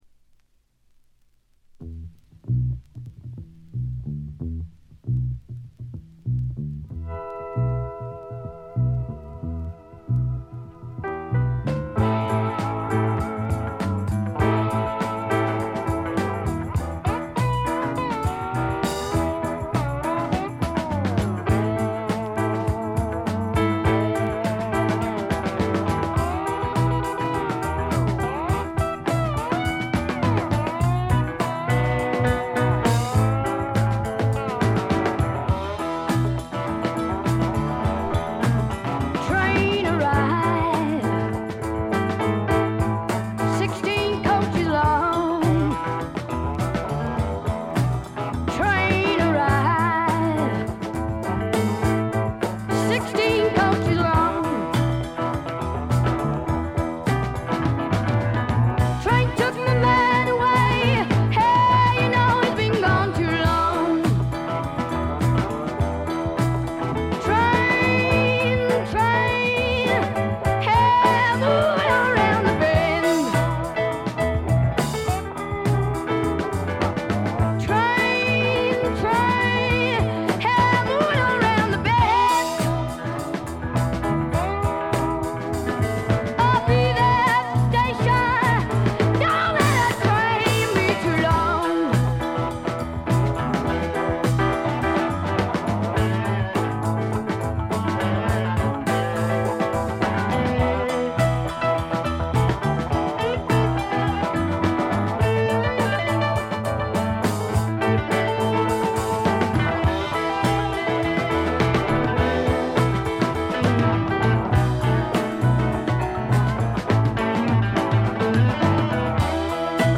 部分試聴ですが、軽微なチリプチ少々。
試聴曲は現品からの取り込み音源です。